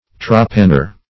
Trapanner \Tra*pan"ner\, n. One who trapans, or insnares.